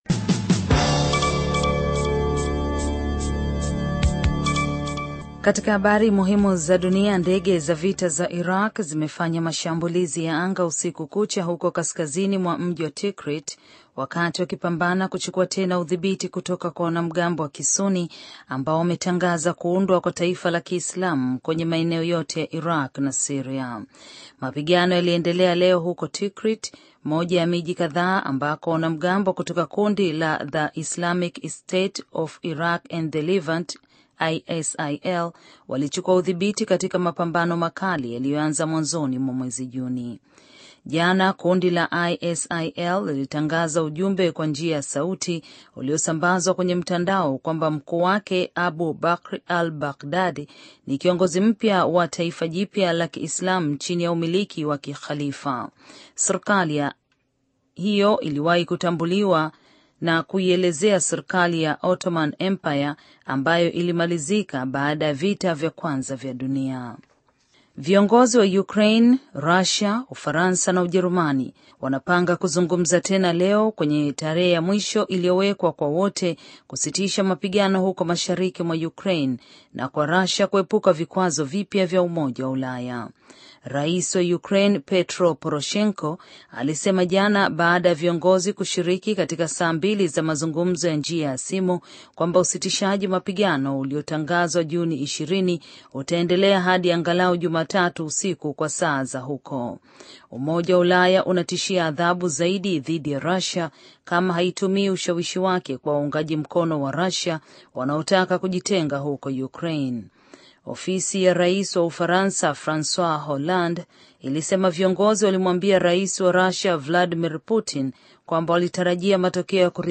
Taarifa ya Habari VOA Swahili - 6:53